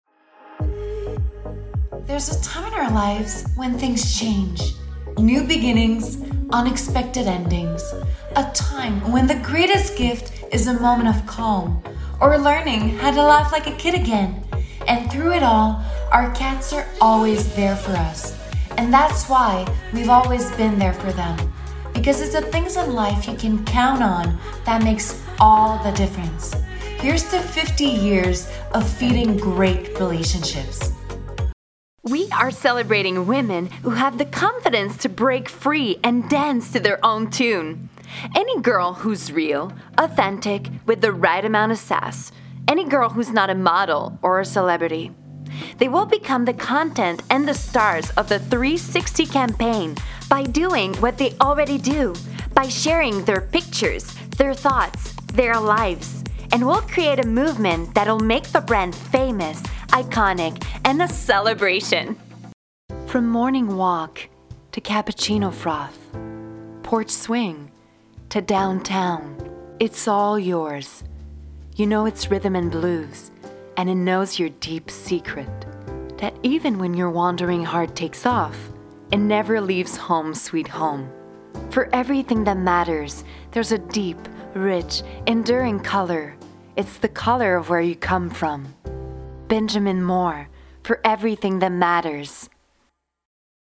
Démo Canada anglo
Voix off
20 - 40 ans - Mezzo-soprano